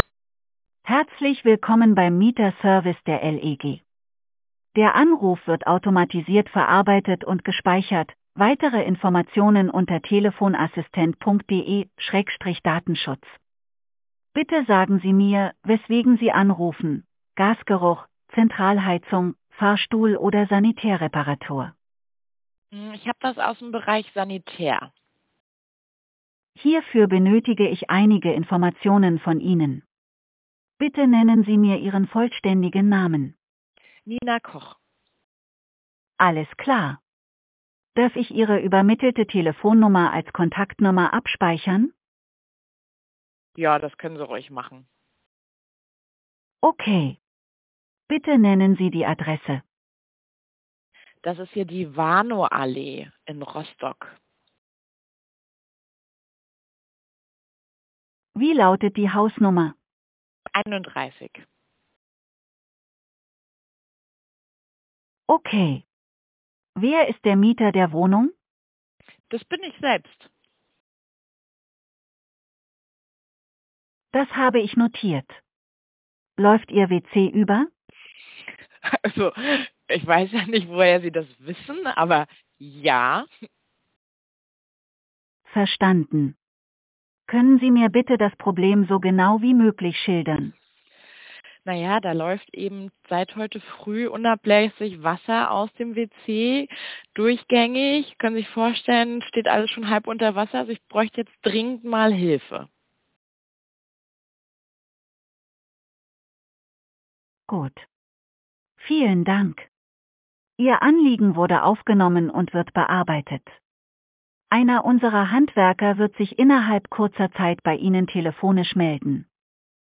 Audio-Schadensmeldung.mp3